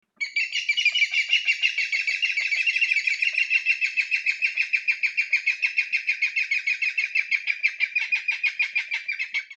「日本の鳥百科」ヤンバルクイナの紹介です（鳴き声あり）。
yanbarukuina_s.mp3